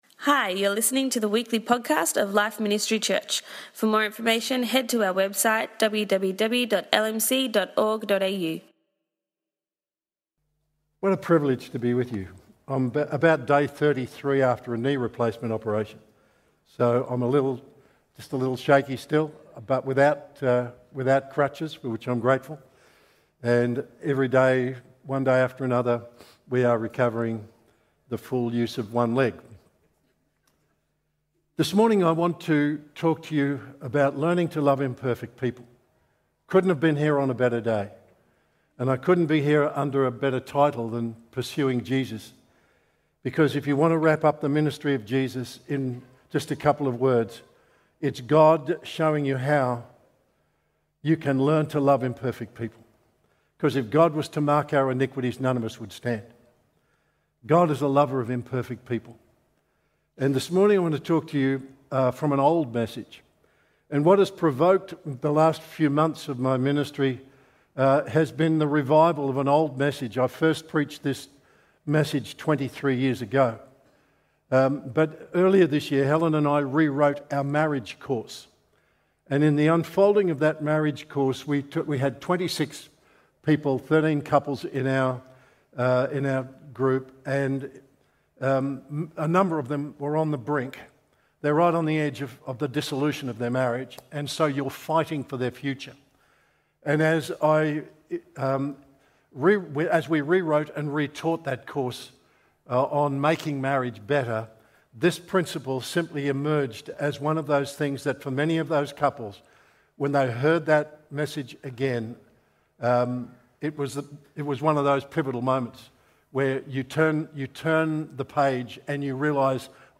Today we heard from guest speaker